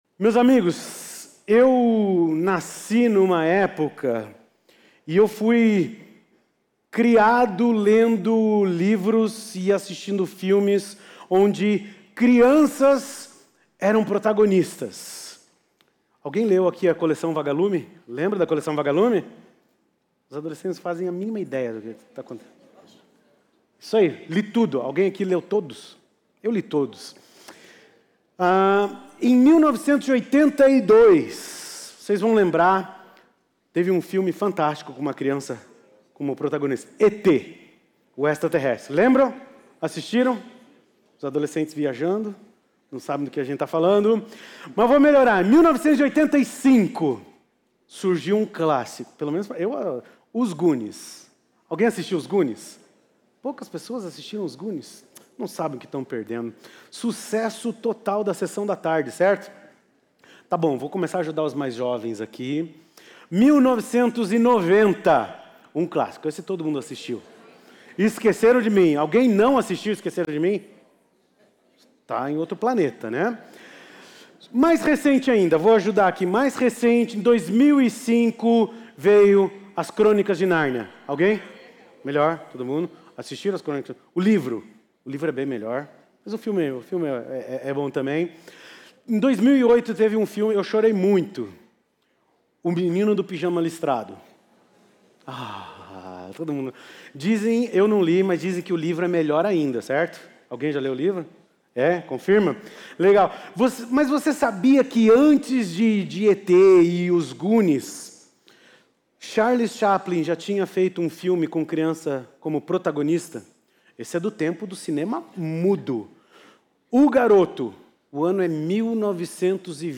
Igreja Batista do Bacacheri